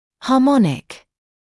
[hɑː’mɔnɪk][хаː’моник]гармоничный